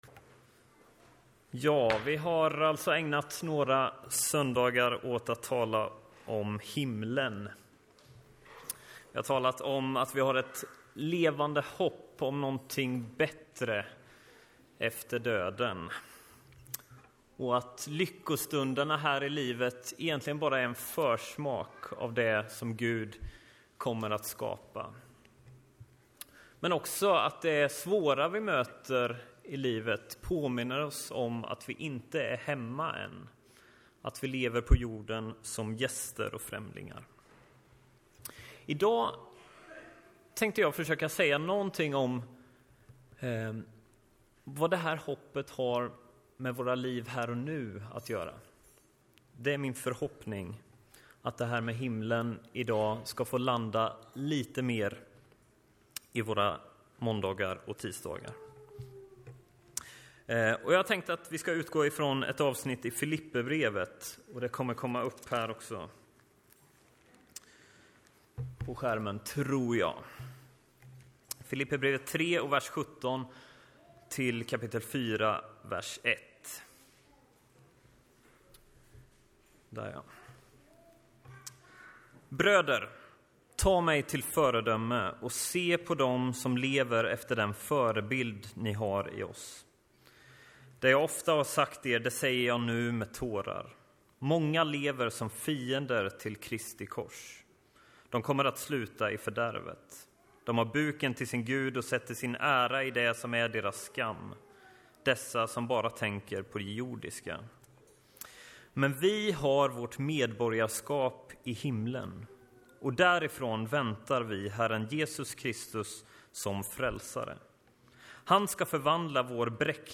Predikoserie: Allting nytt - fyra söndagar om himlen (maj 2015) Etiketterad med Filipperbrevet , Himlen